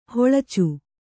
♪ hoḷacu